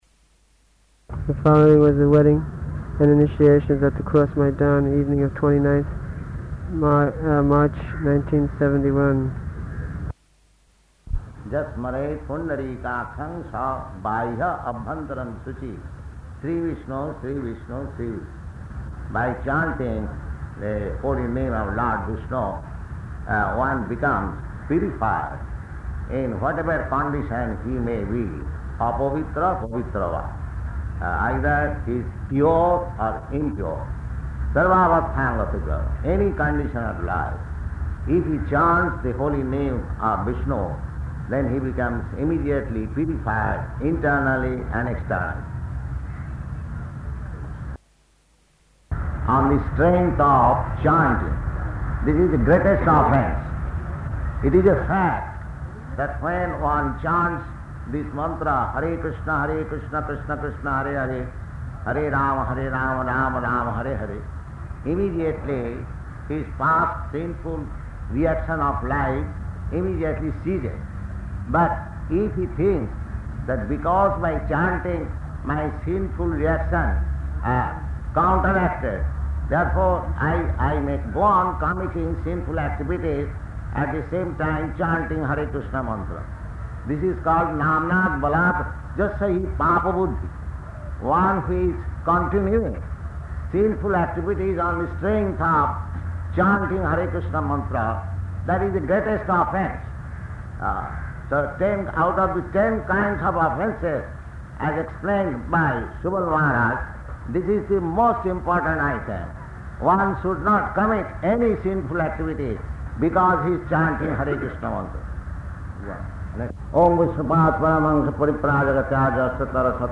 Bhagavad-gītā 7.3 Paṇḍāl Wedding and Initiations at Cross Maidan